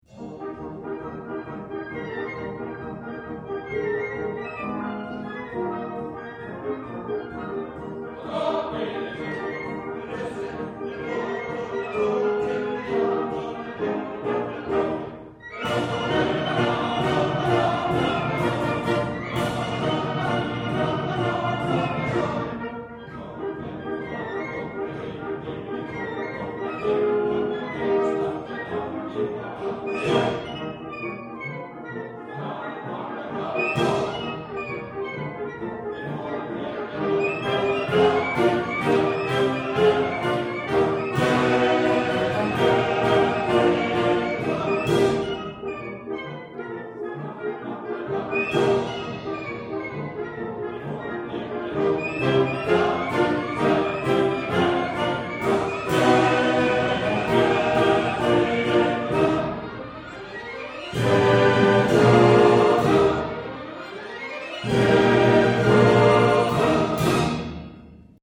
Enregistrement live.
soprano
ténor
baryton